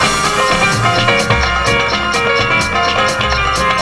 SALSA LOO0BL.wav